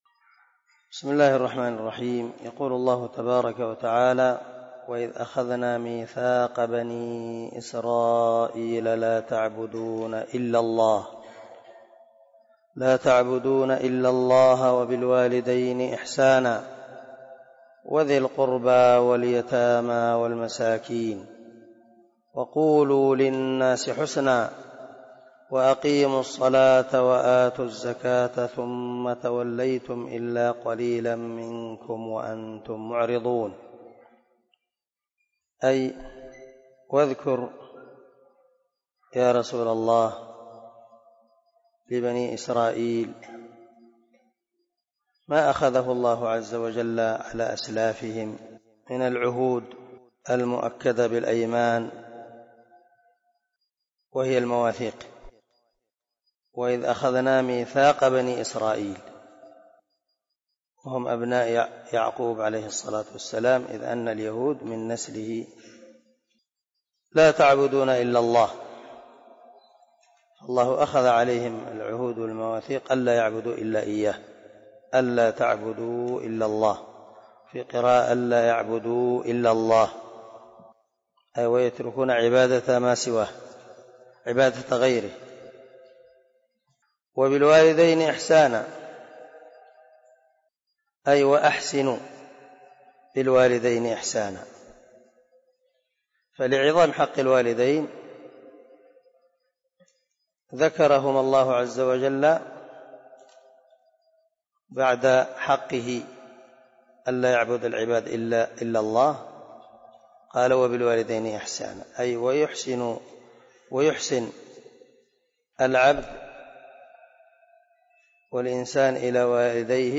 036الدرس 26 تفسير آية ( 83 ) من سورة البقرة من تفسير القران الكريم مع قراءة لتفسير السعدي